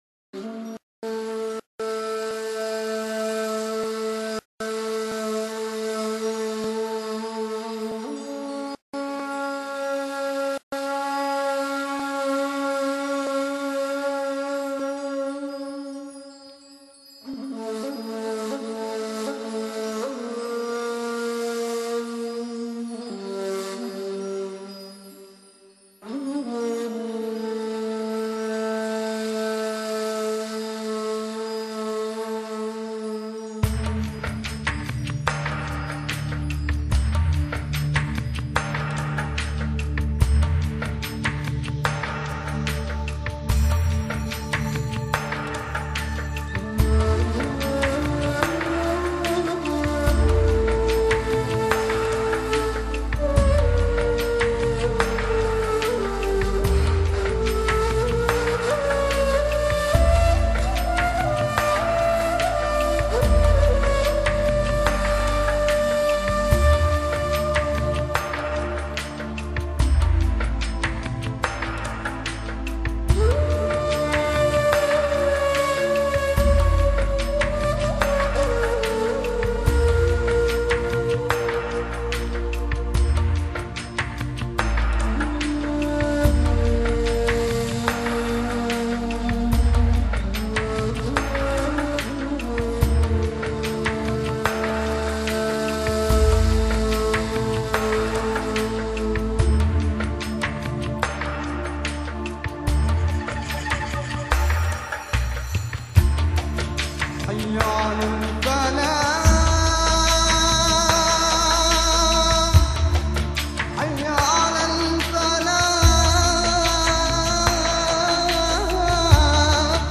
音乐类型: Newage
尺八悠扬的旋律响起，落日的余辉，撒在森林的角落，把满地的落叶染得通红。
金属片的振响沉淀在尺八低沉而圆润的节奏中，电子音乐的旋律，哄托起尺入的低郁。